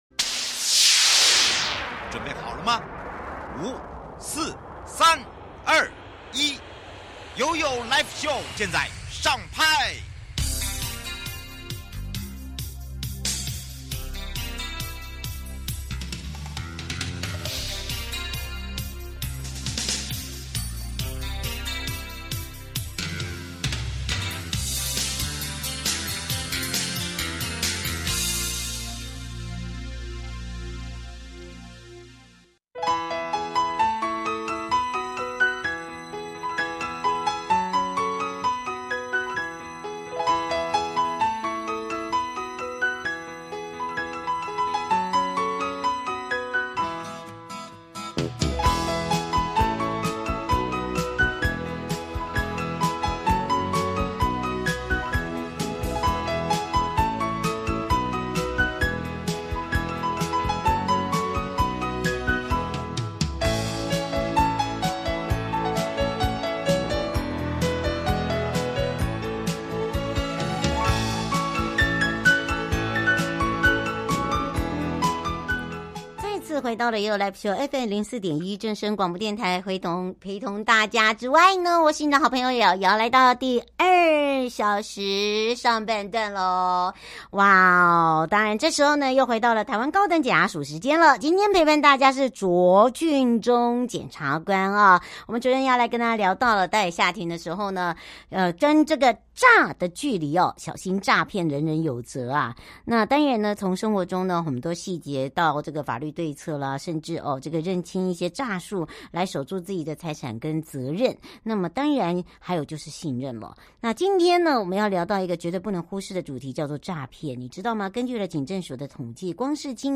今天，我們特別邀請到臺灣高等檢察署卓俊忠檢察官，透過第一線的經驗，帶我們認清詐騙現況與應對方法，一起「與詐保持距離，守護你我財產安全」。